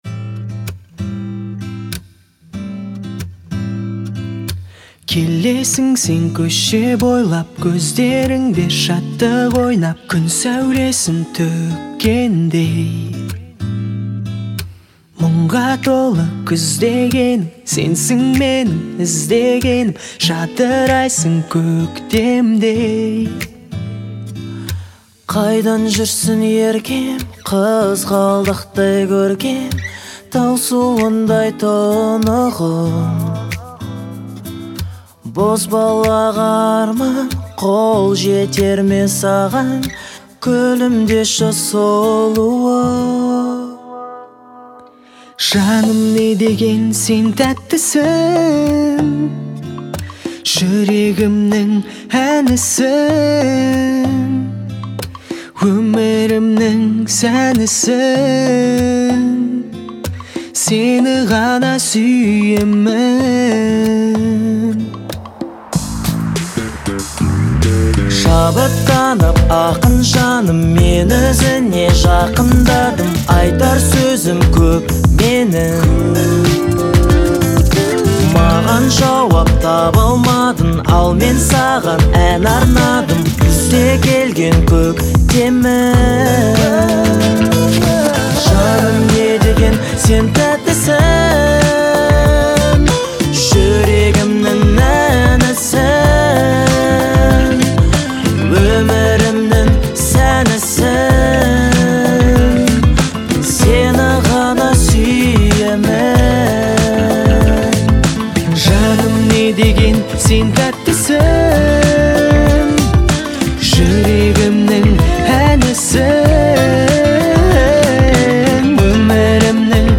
в жанре казахского поп-фолка